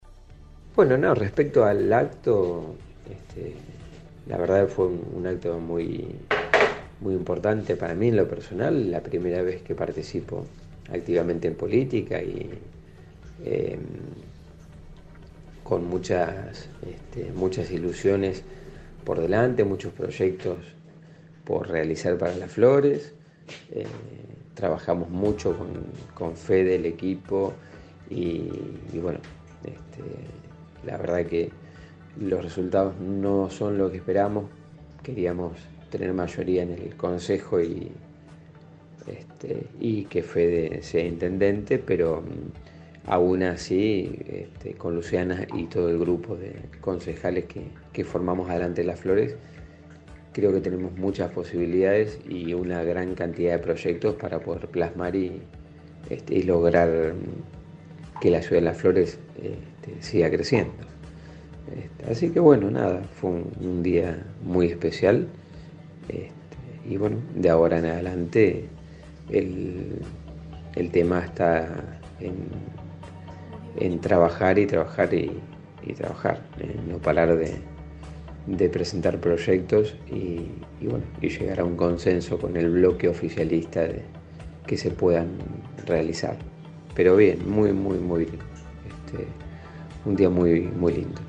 (incluye audios) Los flamantes ediles dejaron este viernes en la 91.5 su reflexión por lo vivido en el marco de la sesión preparatoria que tuvo lugar en la tarde del jueves en el salón «Dr. Oscar Alende» del HCD.